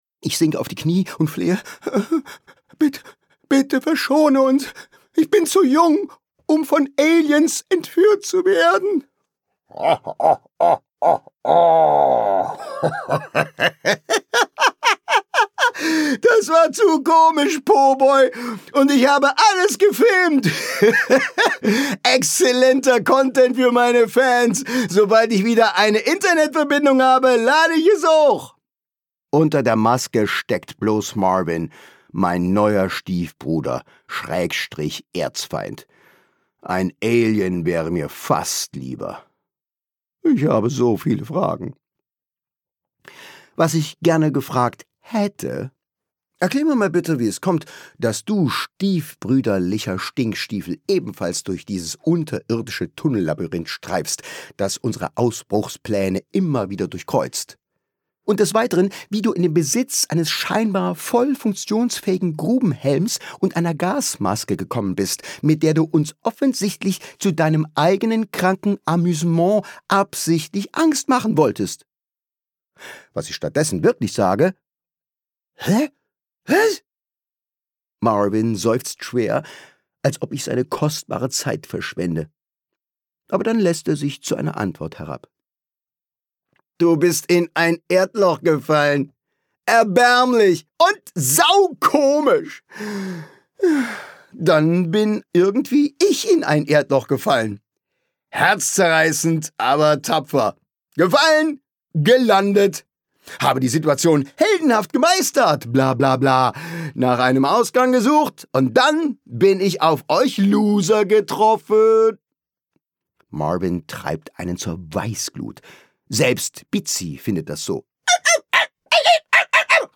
Schule, Peinlichkeiten und ganz viel Humor – Cooles Hörbuch für Kinder ab 10 Jahre